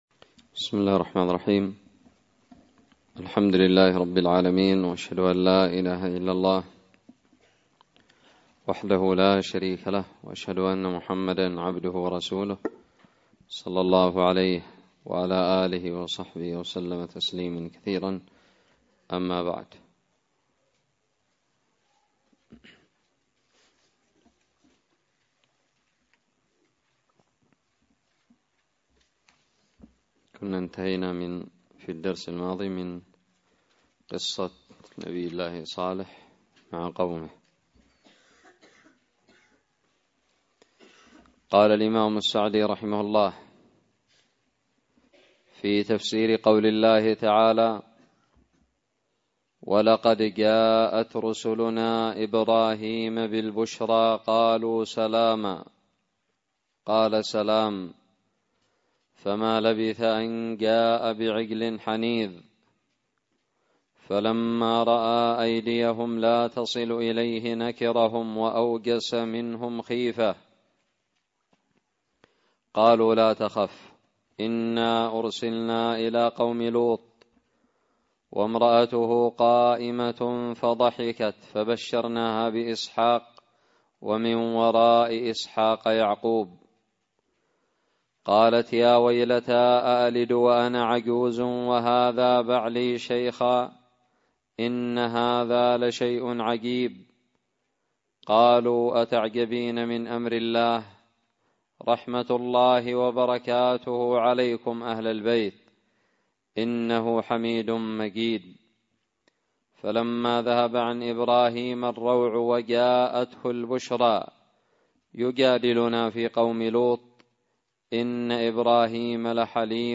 الدرس العشرون من تفسير سورة هود
ألقيت بدار الحديث السلفية للعلوم الشرعية بالضالع